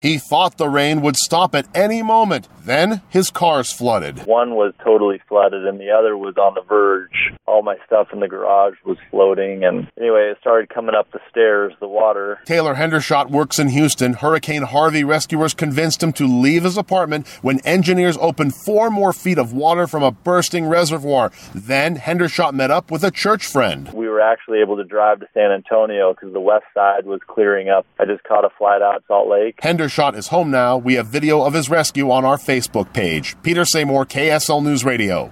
A Salt Lake City man, working in Houston, recounts his reluctance to flee Hurricane Harvey as flood waters encircled him.